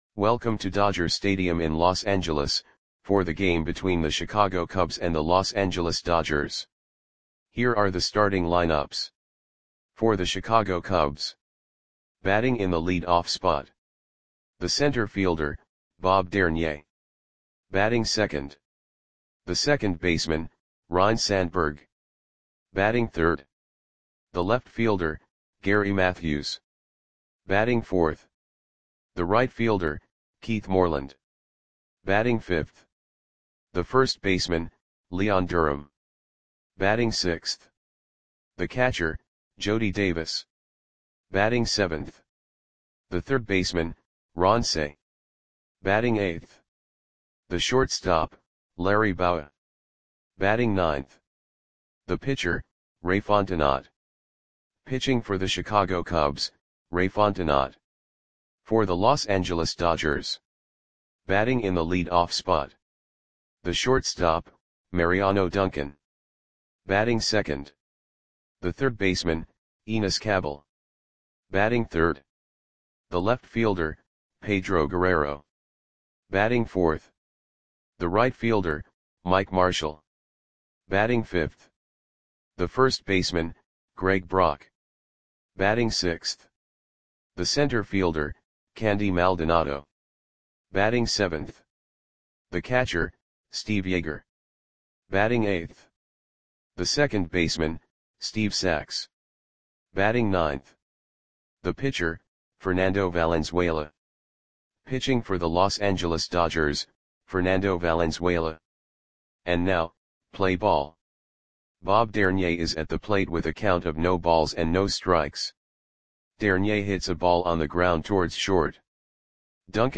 Click the button below to listen to the audio play-by-play.
Cubs 3 @ Dodgers 7 Dodger StadiumJuly 25, 1985 (No Comments)